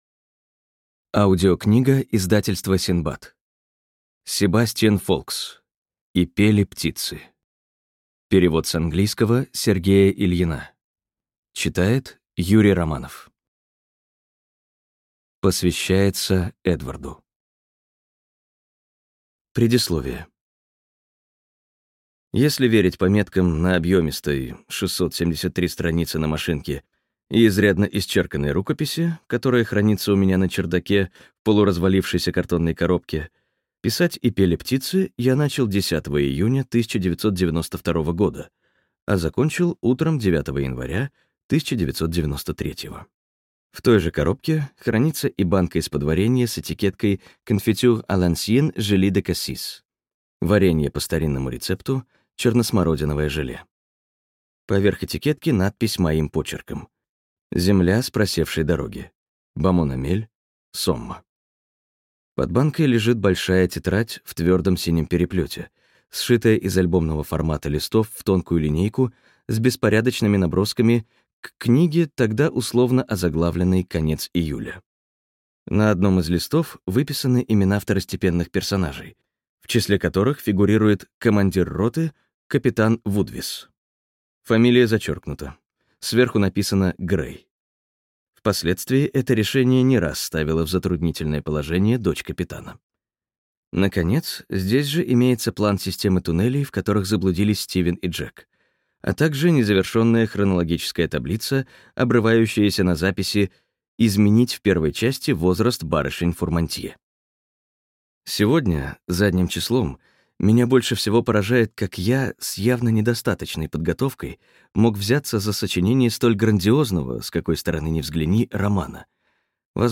Аудиокнига И пели птицы... - Фолкс Себастьян - Скачать книгу, слушать онлайн